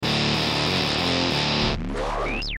描述：吉他与合成器贝斯和一些小的fx混合。